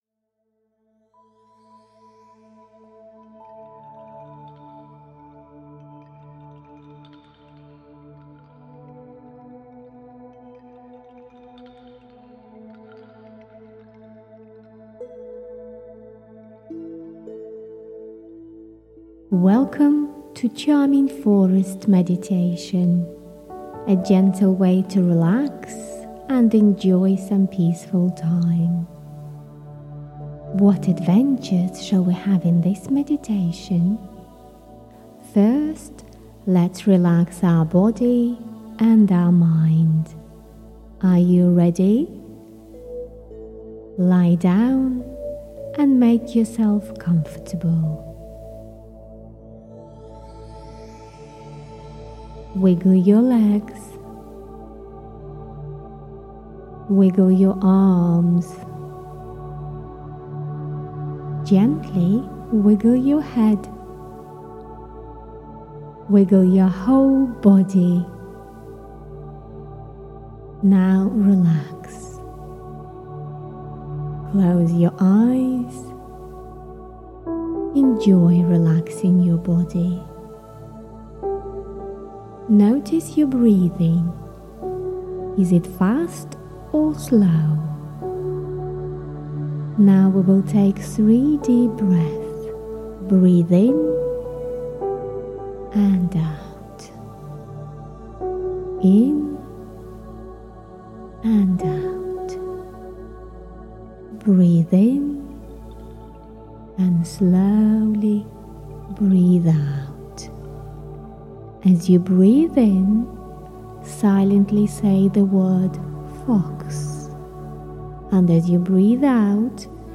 This guided meditation is designed especially for children to help them relax, ease off their worries and feel calm.
The stories in the album are individually crafted with bespoke music, enchanting settings and topics of mental and emotional health, such as: compassion, confidence, dealing with worries and more.
Charming-Forest_meditation_for_kids-copy.mp3